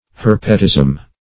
Herpetism \Her"pe*tism\, n. [See Herpes.] (Med.)